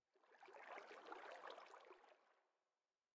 Minecraft Version Minecraft Version latest Latest Release | Latest Snapshot latest / assets / minecraft / sounds / ambient / underwater / additions / water2.ogg Compare With Compare With Latest Release | Latest Snapshot
water2.ogg